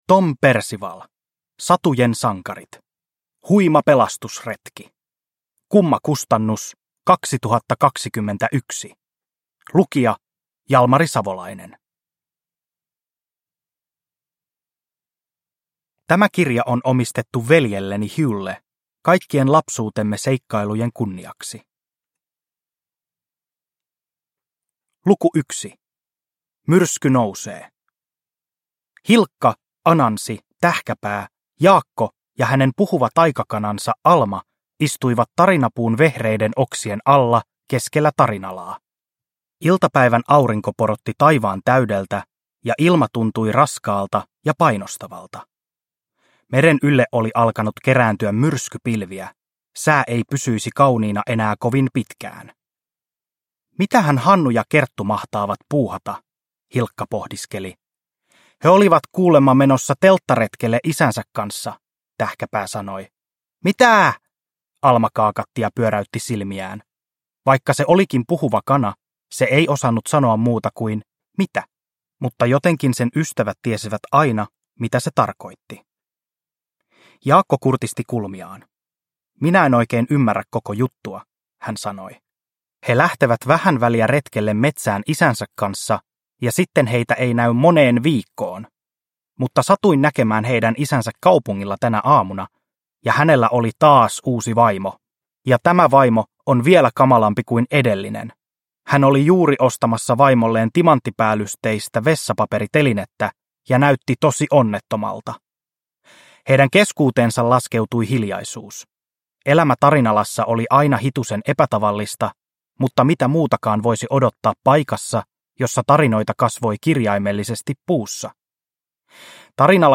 Huima pelastusretki – Ljudbok – Laddas ner